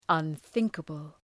Προφορά
{ʌn’ɵıŋkəbəl}